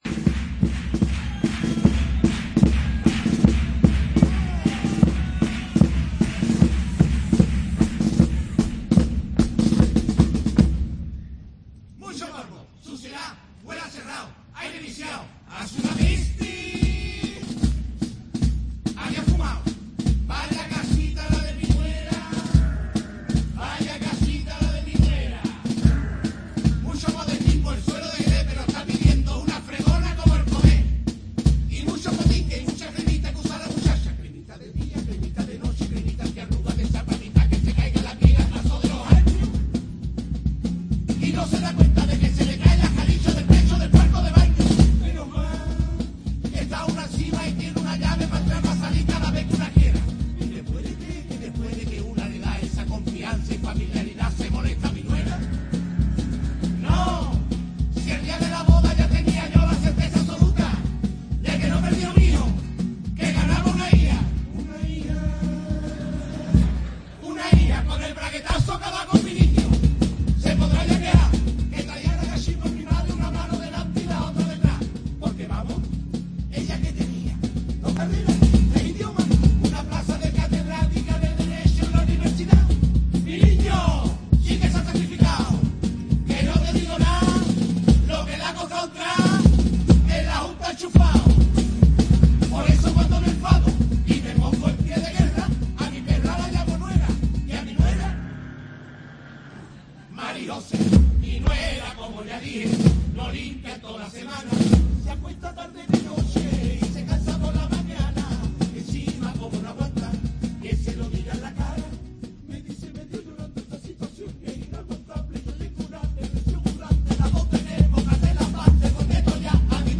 Chirigota "Mi suegra como ya dije"
AUDIO: Semifinales